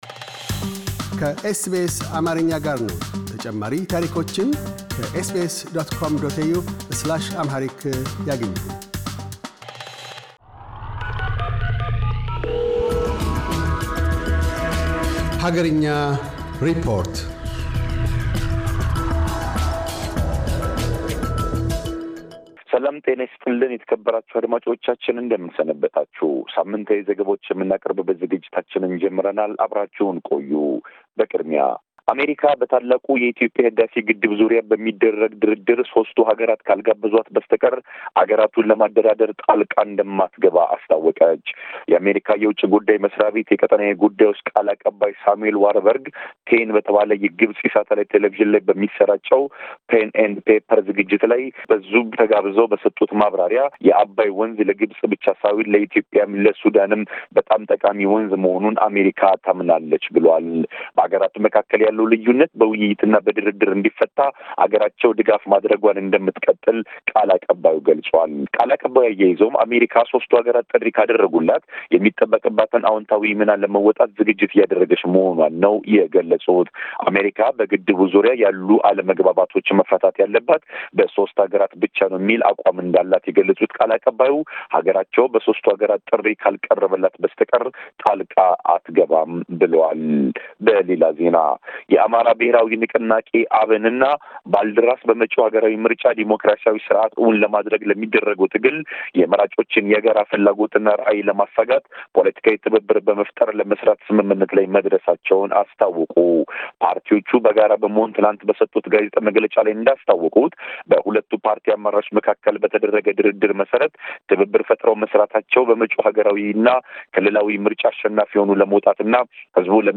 አገርኛ ሪፖርት